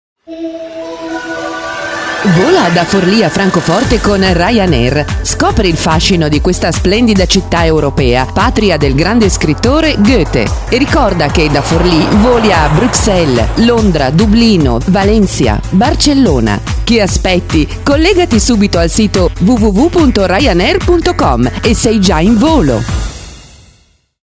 Speaker italiana,voce adulta,trentennale esperienza in spot radiofonici,giornali radio,documentari,conduzione programmi radio.
Kein Dialekt
Sprechprobe: Werbung (Muttersprache):